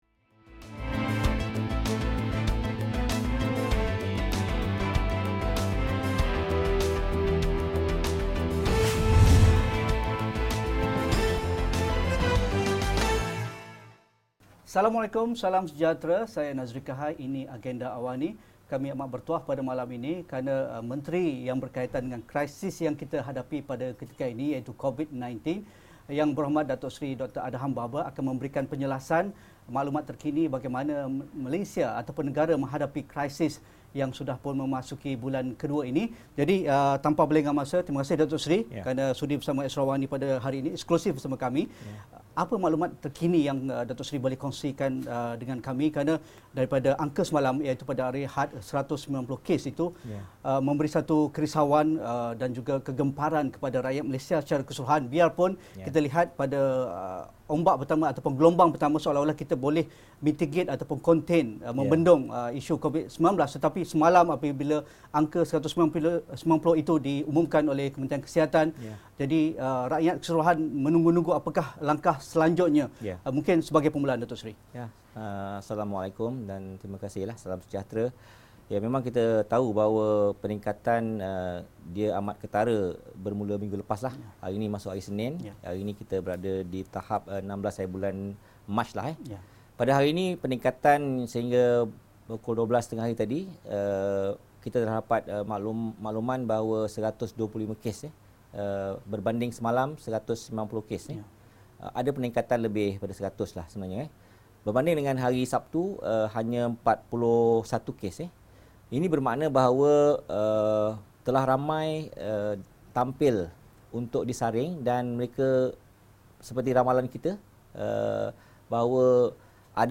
Apakah pelan kerajaan dalam membendung penularannya serta langkah seterusnya? Temubual